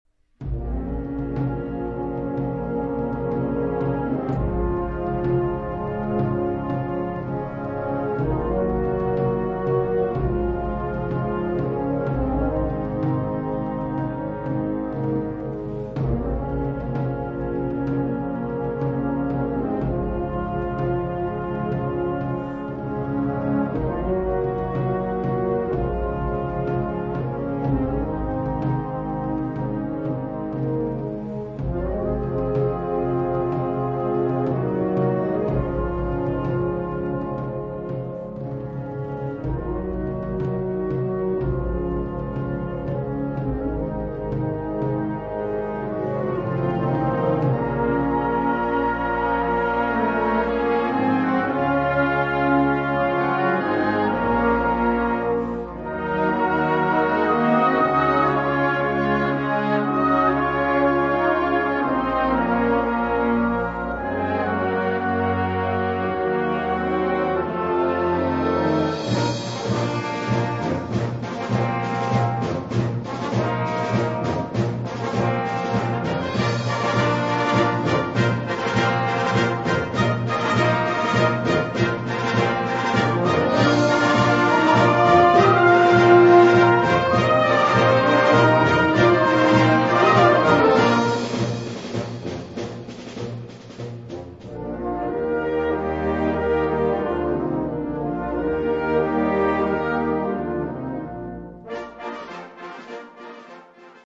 Kategorie Blasorchester/HaFaBra
Unterkategorie Internationale Folklore
Besetzung Ha (Blasorchester)